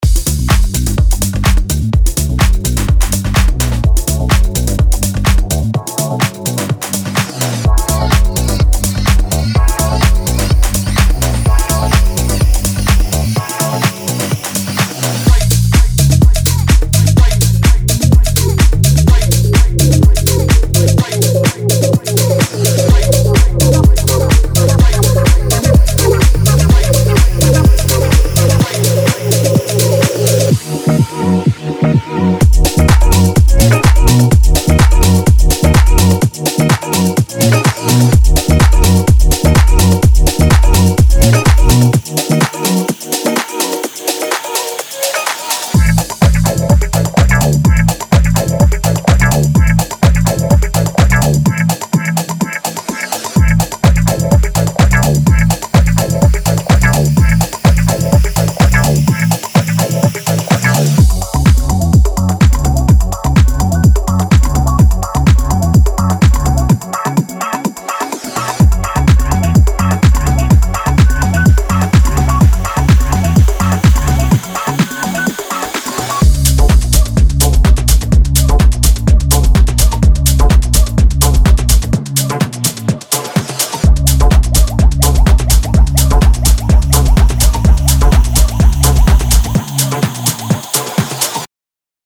デモサウンドはコチラ↓
Genre:Tech House
34 Full Drum Loops
5 Vocal Loops
37 Bass Loops
30 Synth Loops